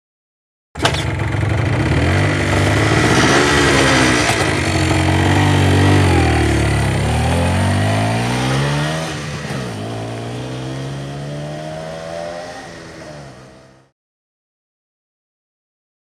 Motorcycle; Start / Away; Moped Start And Slow Away Tr07